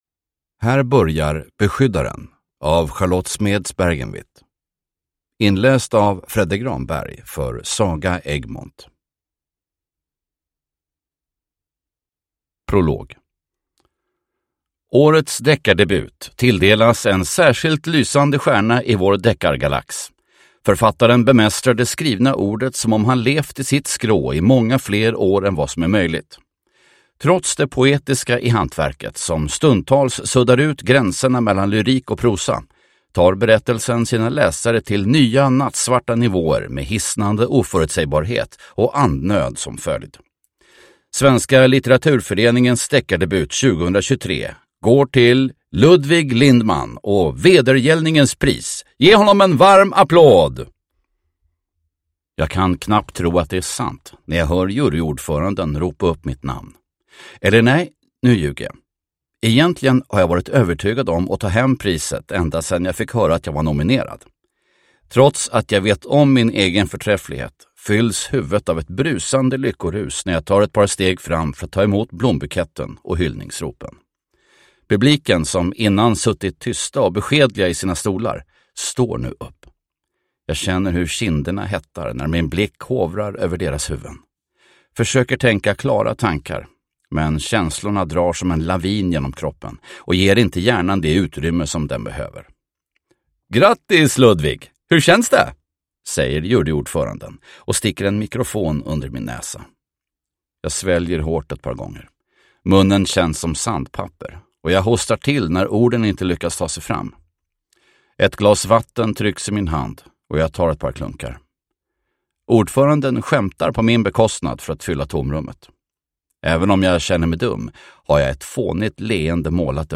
Beskyddaren – Ljudbok